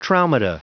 Prononciation du mot traumata en anglais (fichier audio)
Prononciation du mot : traumata